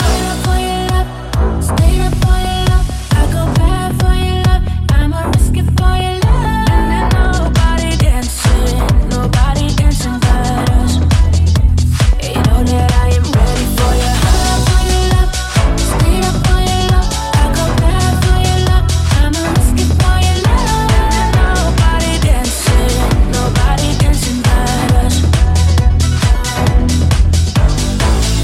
Genere: pop,deep,dance,disco,news